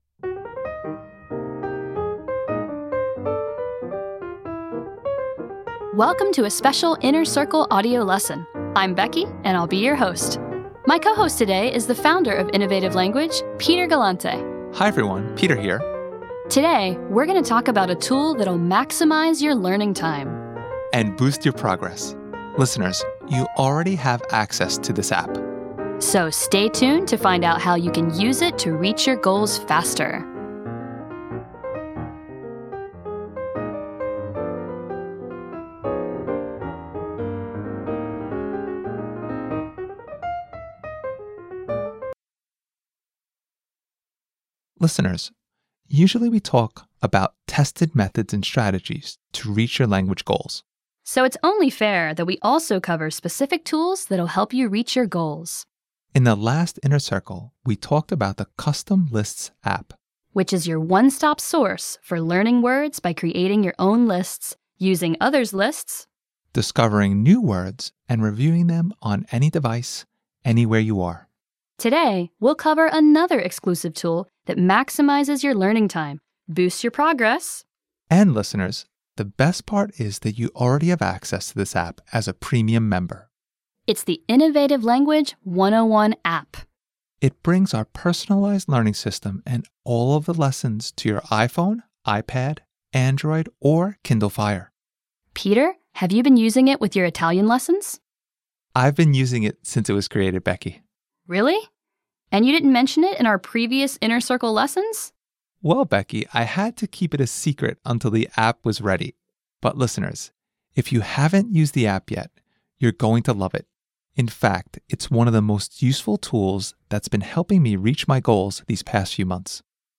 How to take advantage of the Innovative Language 101 App How to schedule routines to maximize your learning time How to boost your progress with harder lessons Audio Lesson Want to keep this lesson?